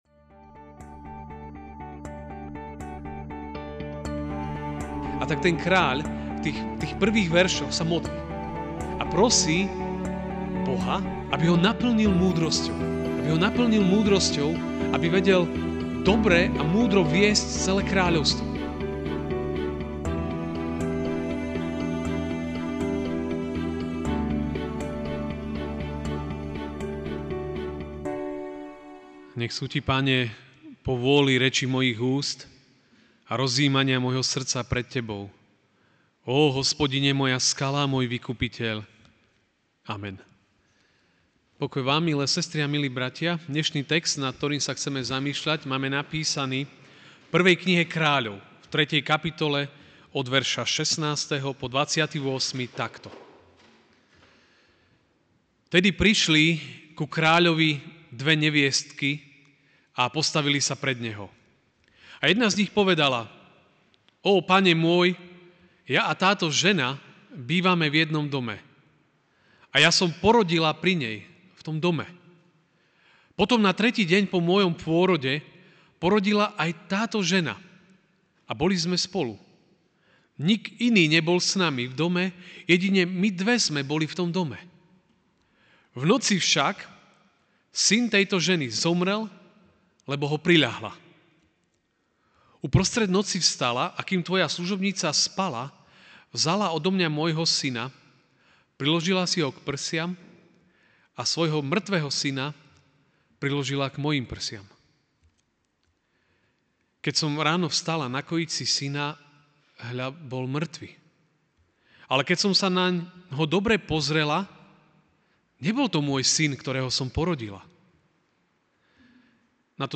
Ranná kázeň: Múdrosť zhora! (1 Kráľov 3,16-28)